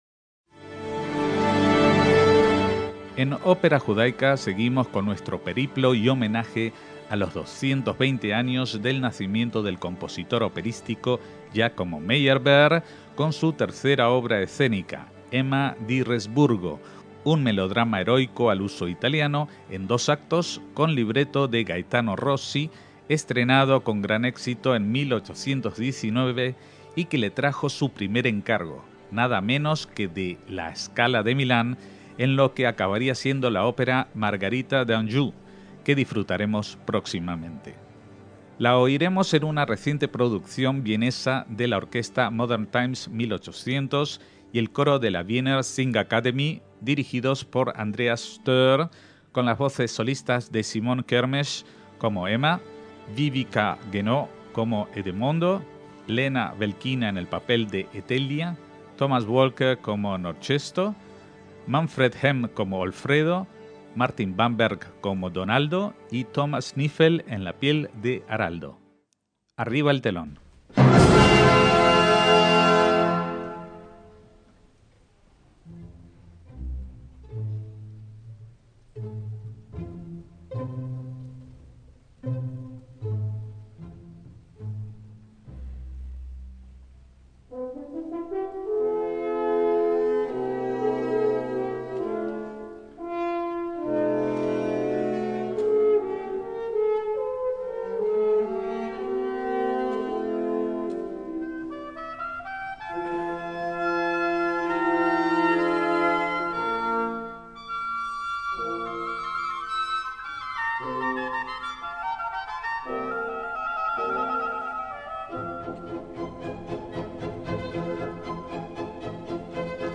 ÓPERA JUDAICA - Seguimos recorriendo las óperas con las que Meyerbeer se consagró hace poco más de un siglo.